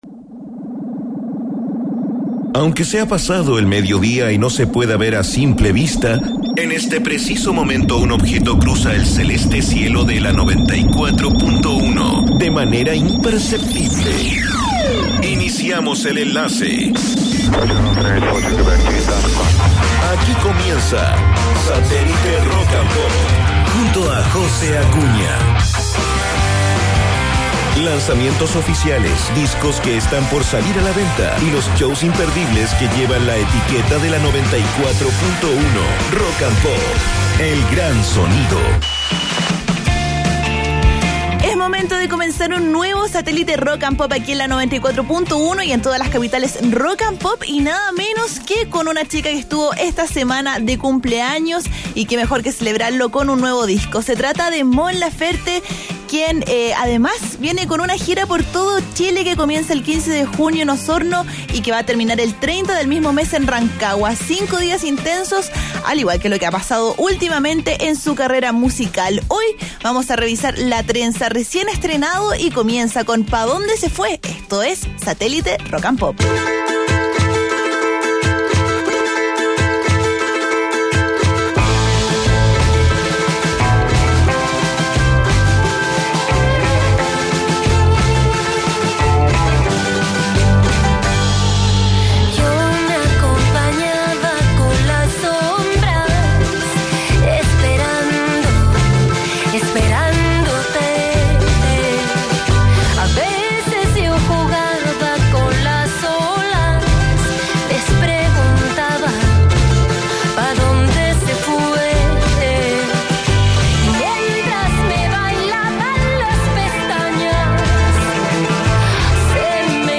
Un viaje perfecto hecho de ritmos latinoamericanos.